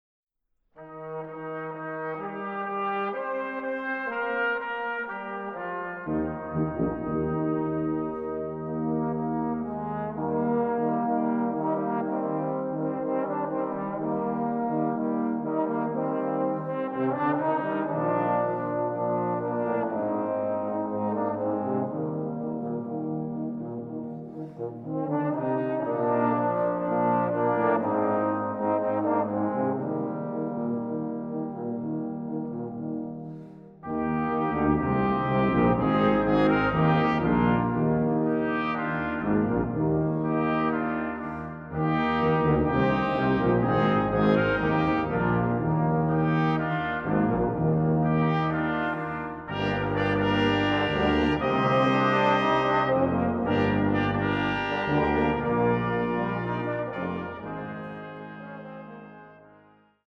Musik für Posaunenchor und Blechbläserensemble